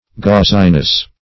Gauziness \Gauz"i*ness\, n. The quality of being gauzy; flimsiness.